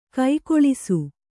♪ kai koḷisu